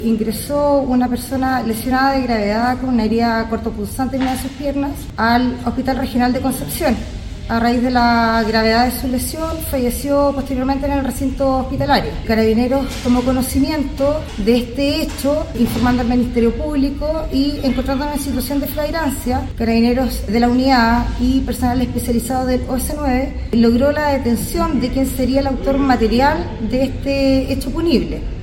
carabinera-homicidio-hombre-concepcion.mp3